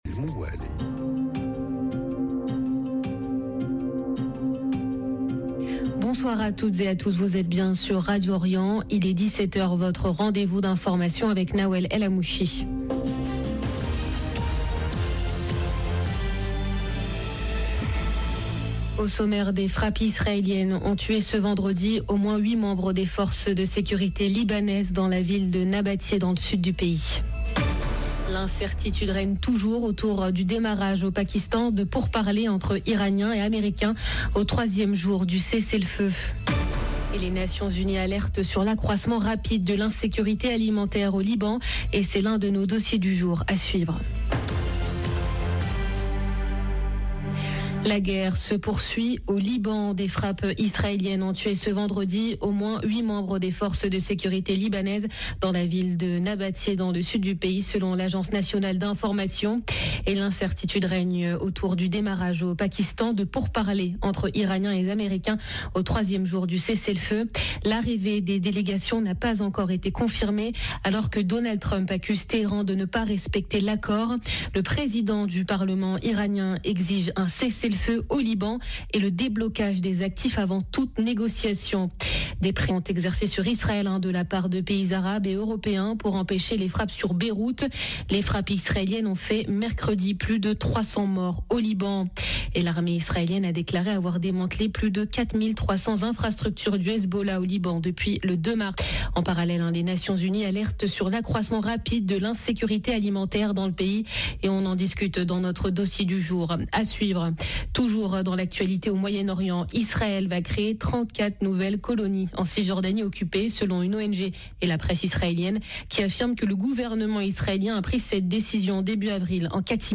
Journal de 17H